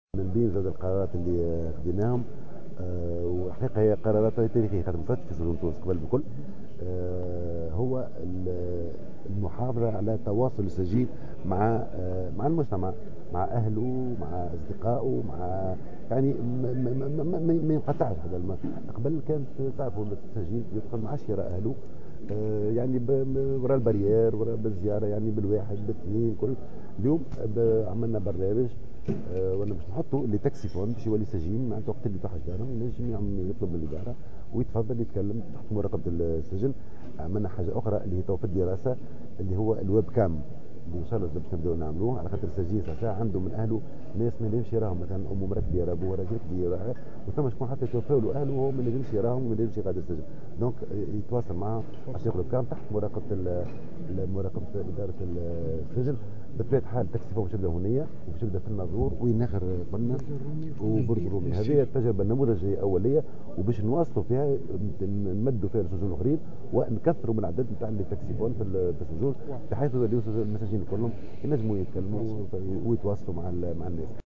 قال وزير العدل عمر في تصريح لمراسل الجوهرة "اف ام" على هامش زيارة أداها مساء اليوم إلى ولاية المهدية أن الوزارة بصدد دراسة فكرة مشروع يمكن المساجين من التواصل مع عائلاتهم عبر "الواب كام" وذلك بعد تركيز أجهزة "تاكسيفون" في عدد السجون التونسية لتمكين السجناء من الاتصال بذويهم.